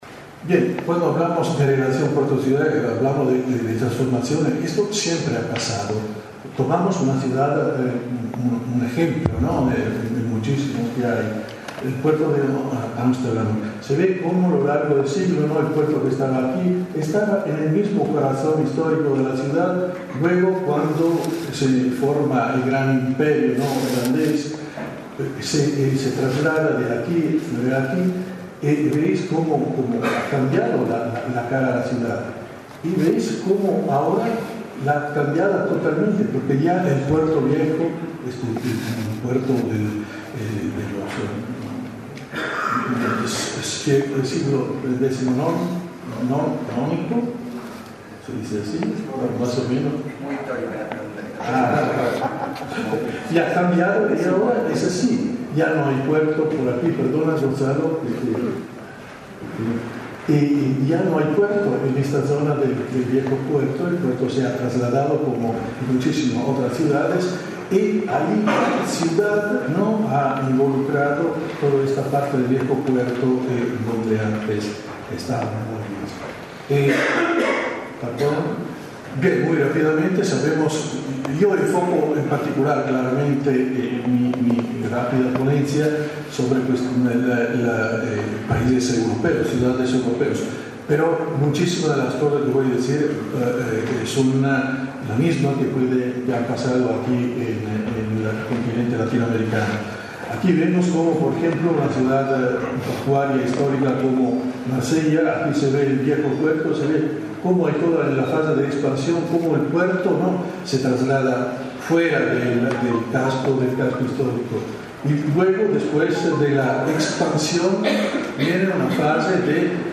Desde el Consejo Portuario Argentino se realizó  el “II Encuentro Multisectorial”.